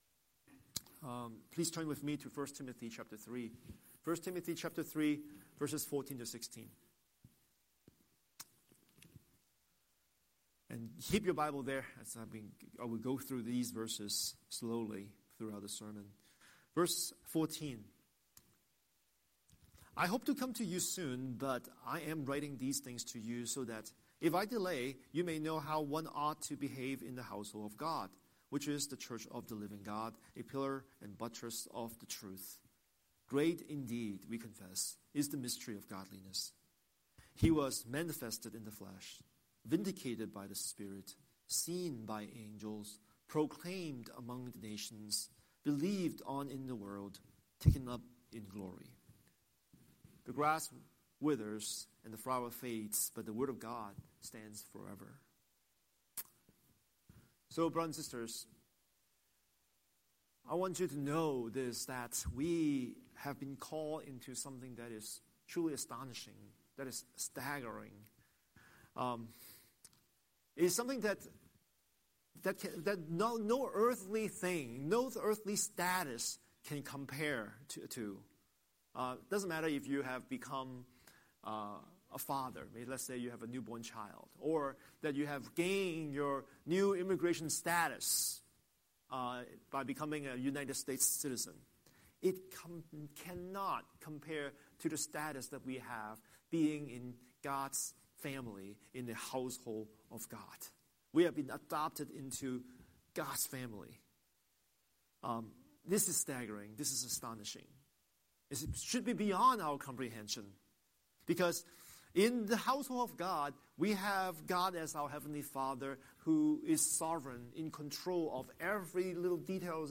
Scripture: 1 Timothy 3:14–16 Series: Sunday Sermon